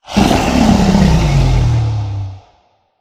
growl1.ogg